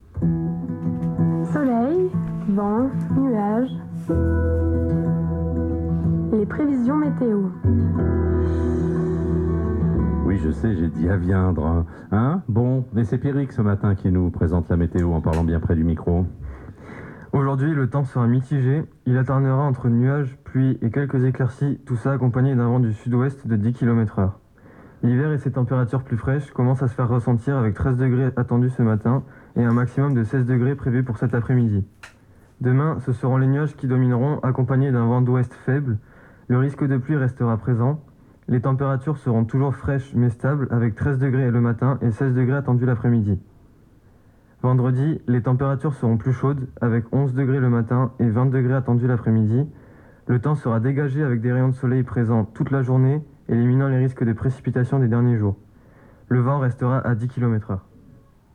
1er bulletin météo mis en voix